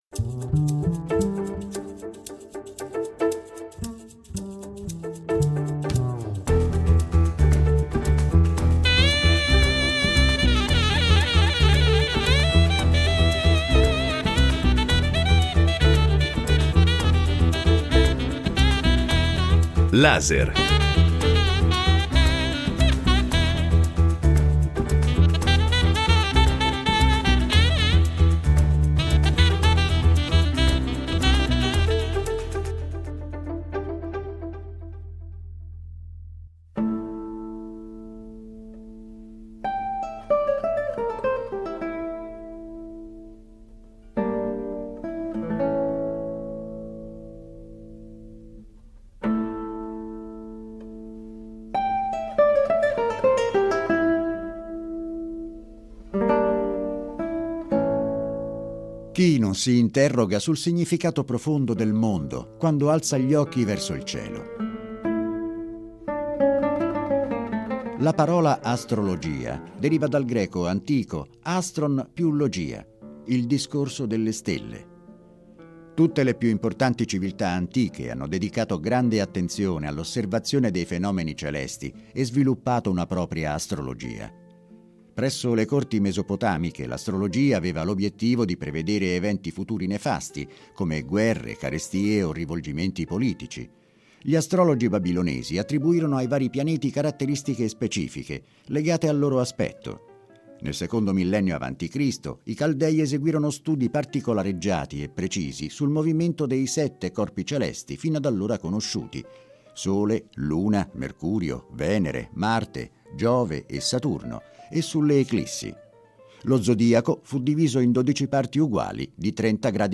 Una chiacchierata